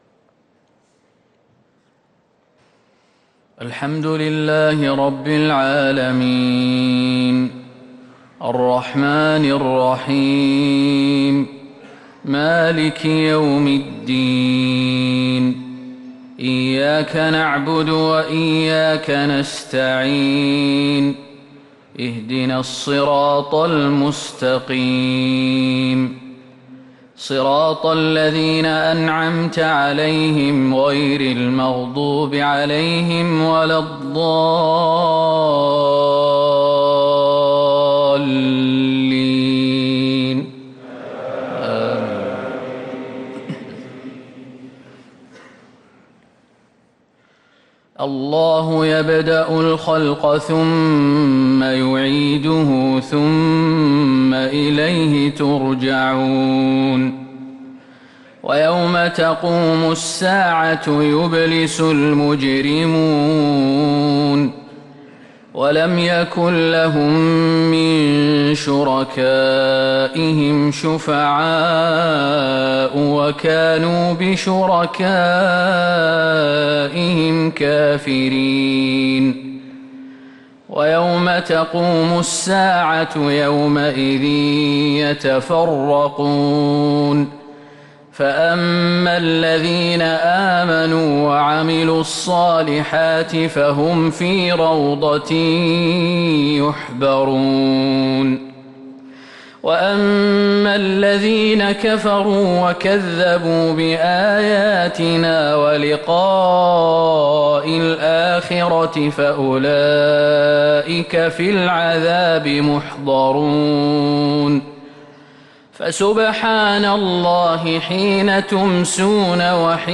صلاة الفجر للقارئ خالد المهنا 18 ربيع الآخر 1444 هـ
تِلَاوَات الْحَرَمَيْن .